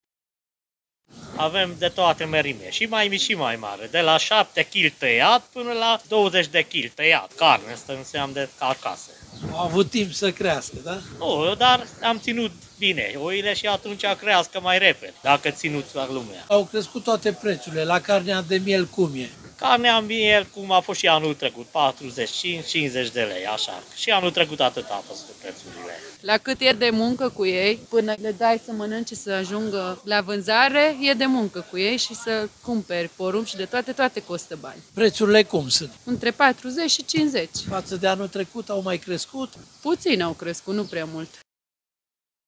Duminică este Paștele Catolic, iar crescătorii de miei spun că au fost nevoiți să ridice prețul față de anul trecut, din cauza cheltuielilor din ultima vreme.